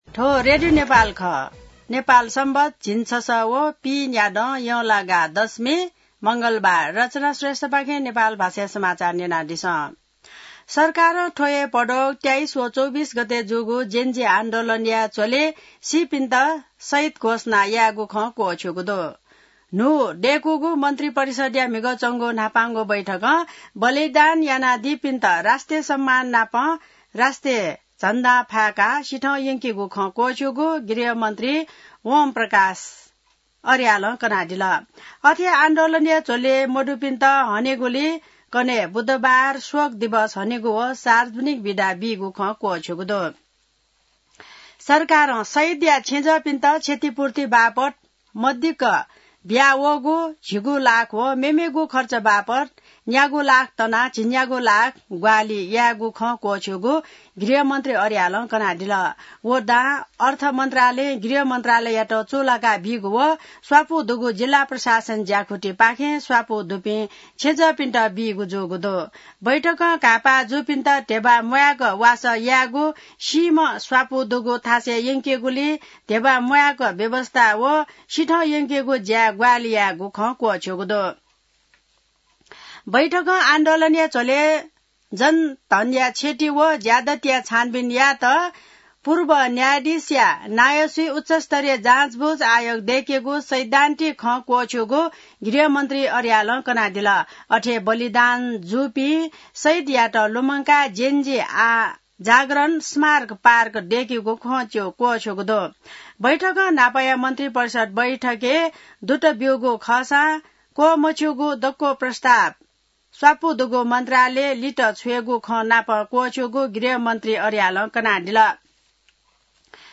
नेपाल भाषामा समाचार : ३१ भदौ , २०८२